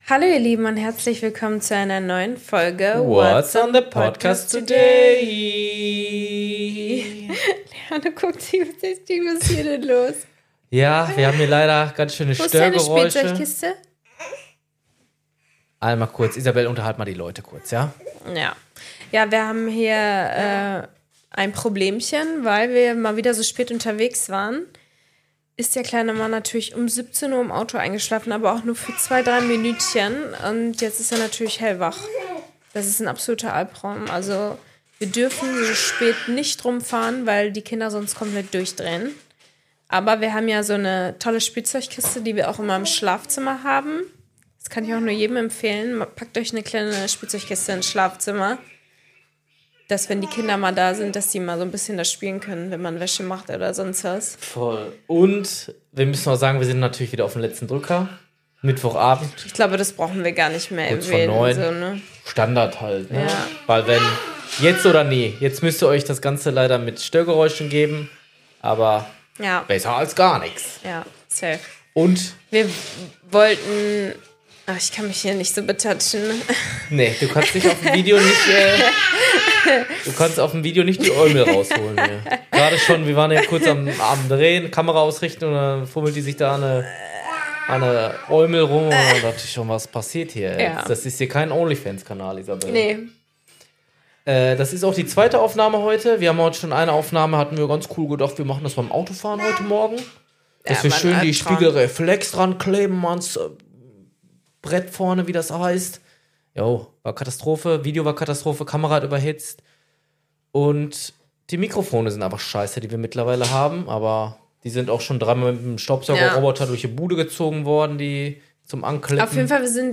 Es wird wieder eine wilde Folge mit geworfenem Spielzeug bis zu Winnie Puh im Bild :D